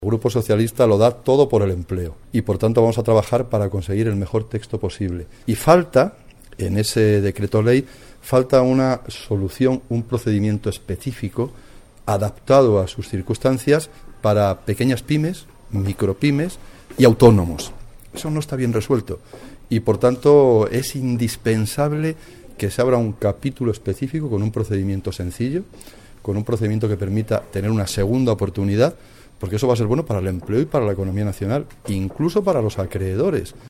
Declaraciones de Jesús Caldera tras reunirse en el Congreso con los representantes de las asociaciones de autónomos y PYME 23-09-14